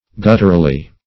Search Result for " gutturally" : Wordnet 3.0 ADVERB (1) 1. in a guttural manner ; - Example: "gutturally articulated" The Collaborative International Dictionary of English v.0.48: Gutturally \Gut"tur*al*ly\, adv.